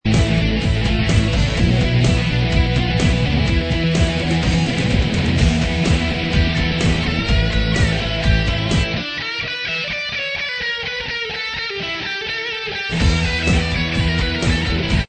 heavy métal